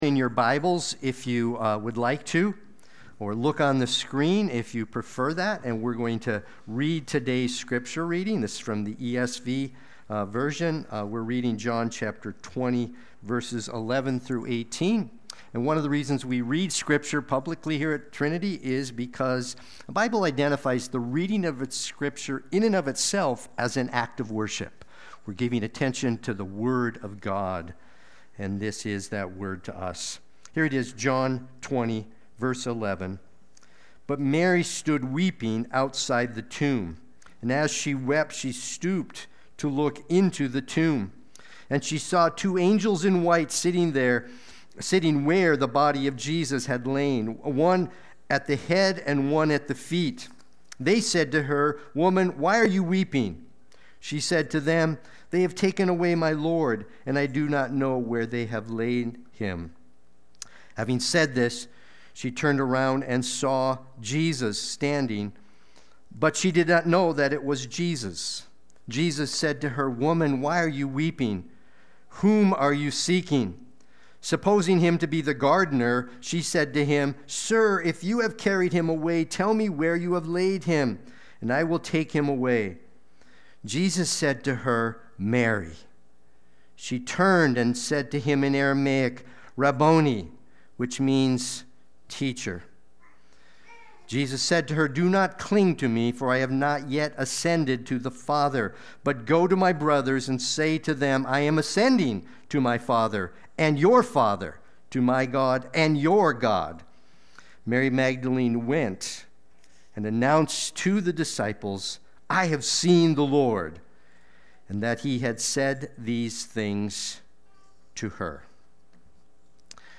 Watch the replay or listen to the sermon.
Sunday-Worship-main-3925.mp3